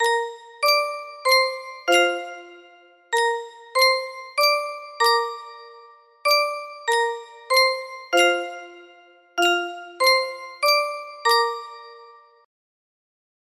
Sankyo Music Box - Westminster Chimes FD music box melody
Full range 60